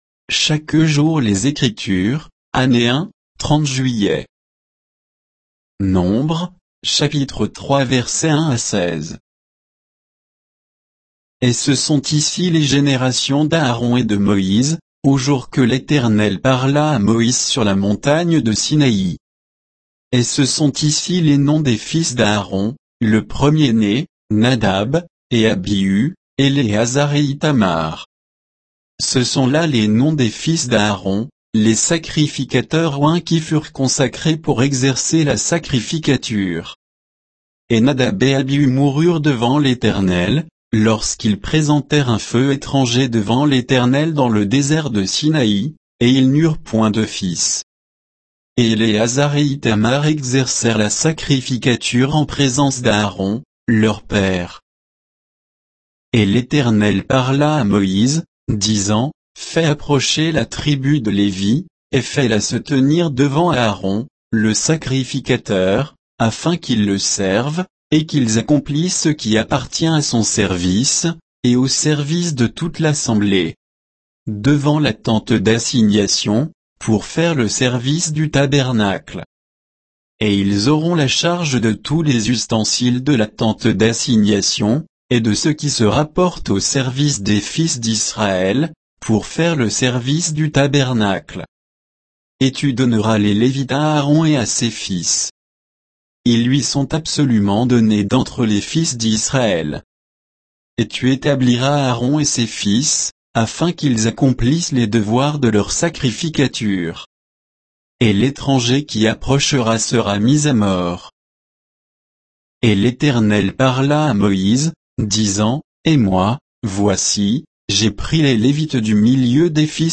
Méditation quoditienne de Chaque jour les Écritures sur Nombres 3